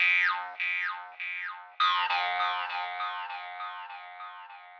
描述：西伯利亚犹太人的竖琴
Tag: 100 bpm Dance Loops Woodwind Loops 826.96 KB wav Key : Unknown